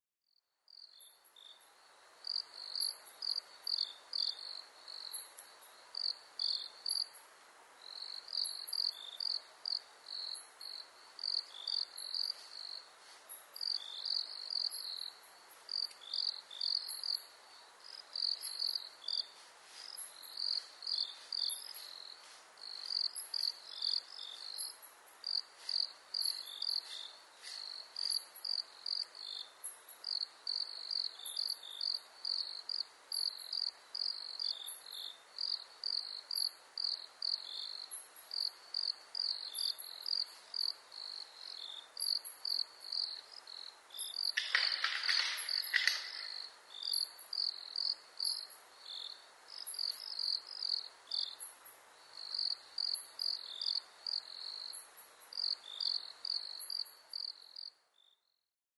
コウモリ sp.　a kind of a Bat
Mic.: Sound Professionals SP-TFB-2  Binaural Souce
他の自然音：　 ツヅレサセコオロギ・四つ足動物の足音